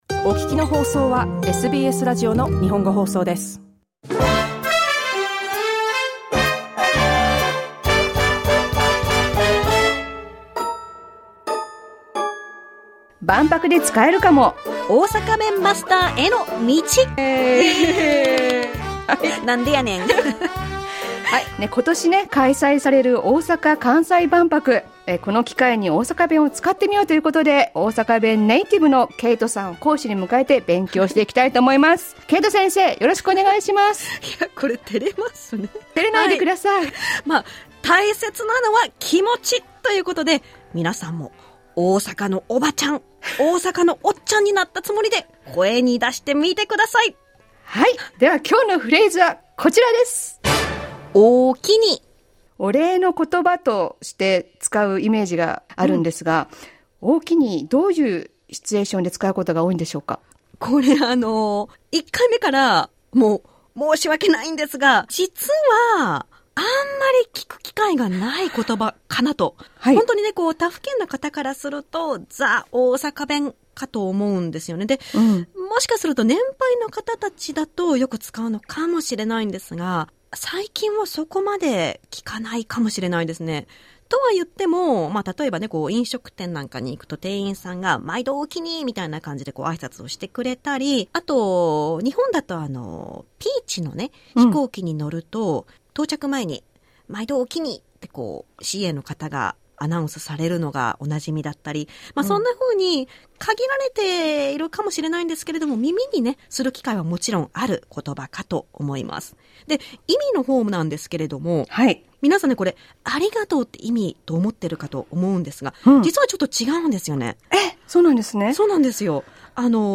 SBS Japanese's segment to learn Osaka dialect.